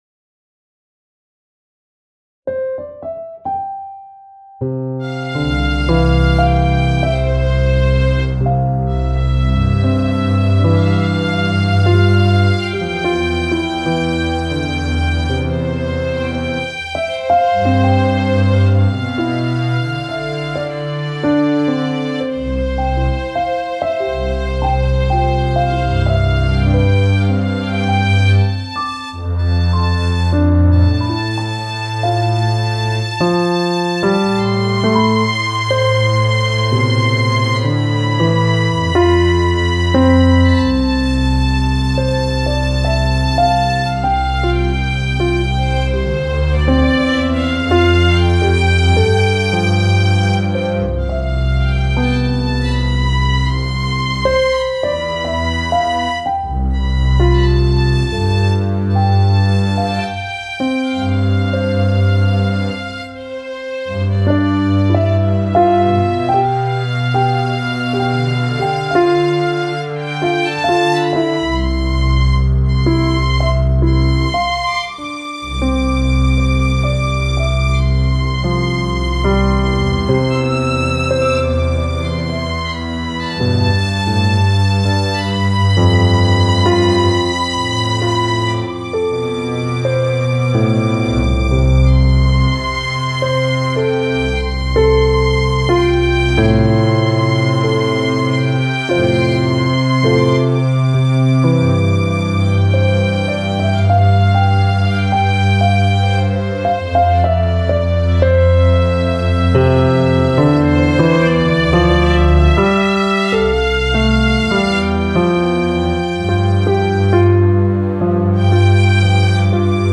eMastered_Pian-Double-Badd-Violin.mp3